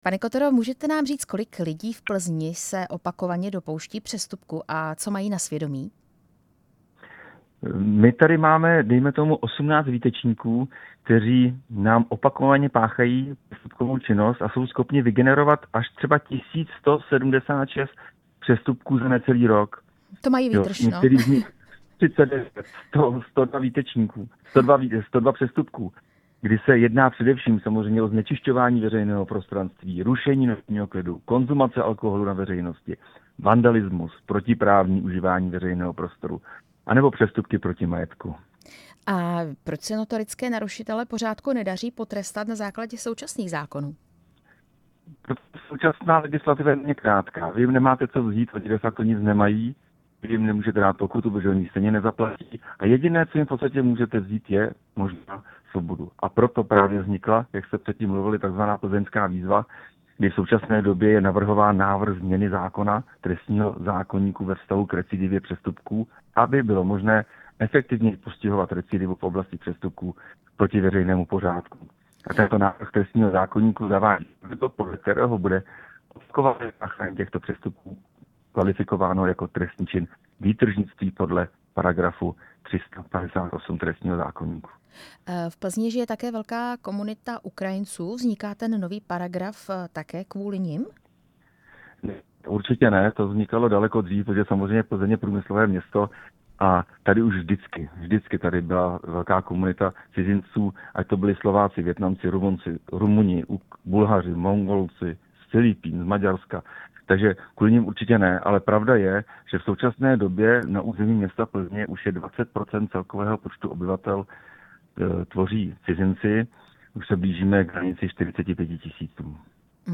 Ve vysílání Radia Prostor jsme se na to ptali Tomáše Kotory, plzeňského radního pro bezpečnost z hnutí Pro Plzeň.
Rozhovor s plzeňským radním Tomášem Kotorou